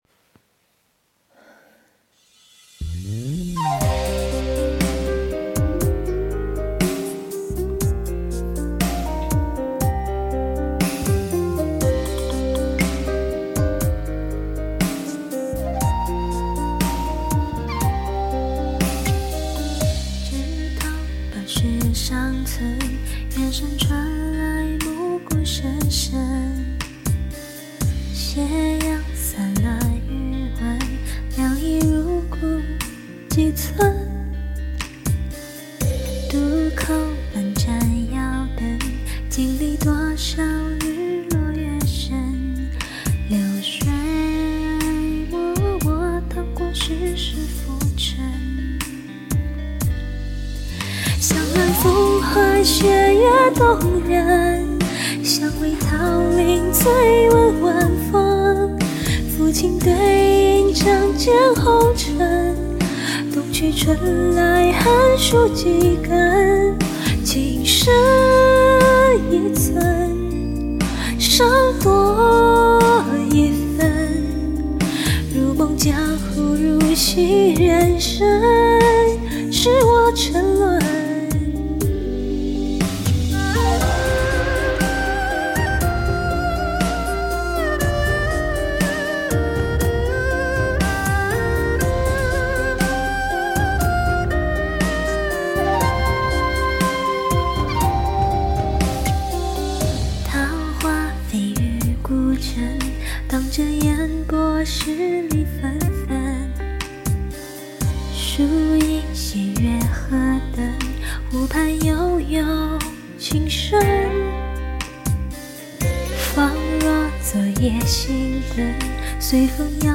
无视我的唉声叹气和唱不上去的篡改